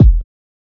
Skyfall Kick.wav